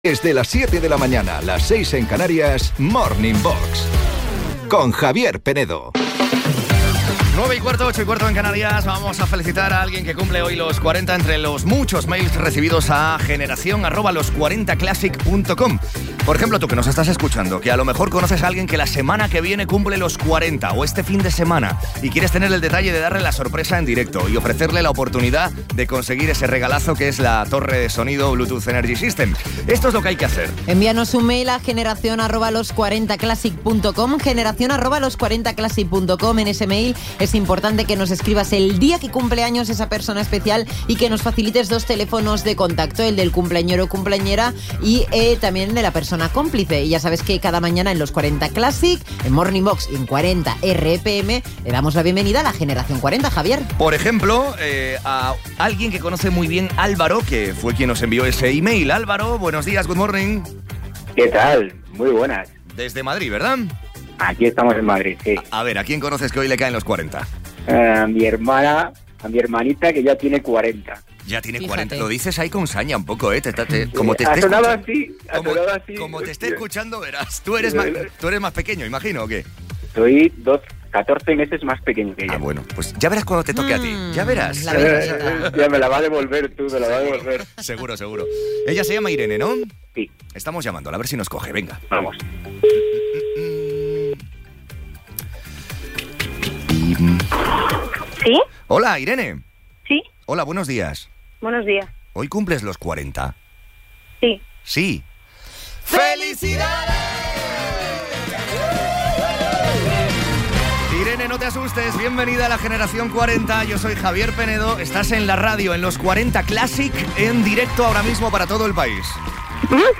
El otro día llamé a un programa de radio para felicitar el cumpleaños de mi hermana. Por sorpresa, en directo y…para toda España!